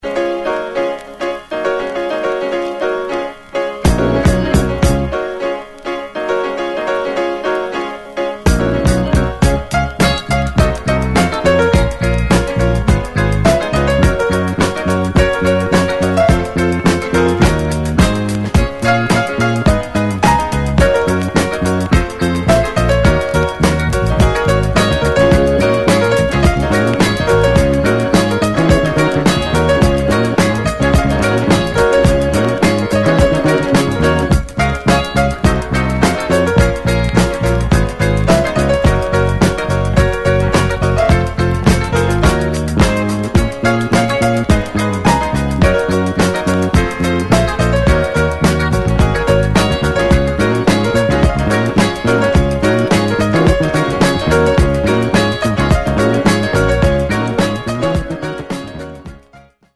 Funk Classic！